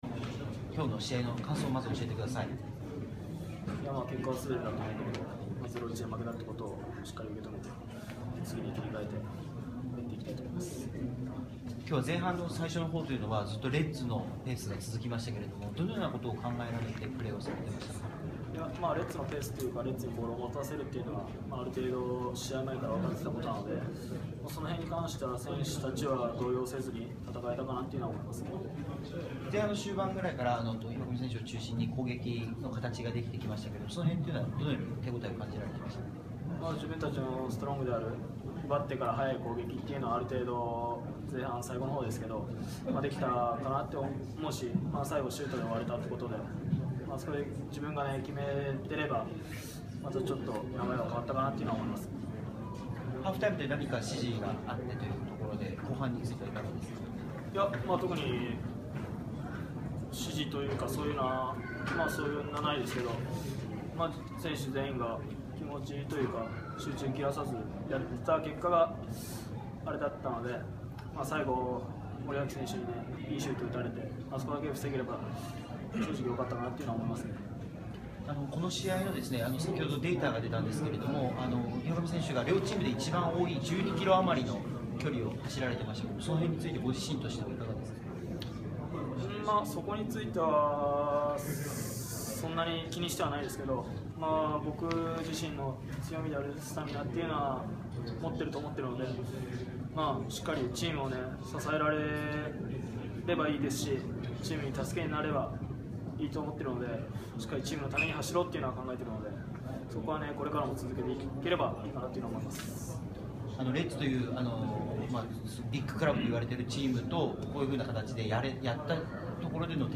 2015 J1 1st 1節 4節 vs浦和レッズ戦 岩上 祐三インタビュー インタビュー 無料コンテンツ 無料 こちらのコンテンツは音声のみです。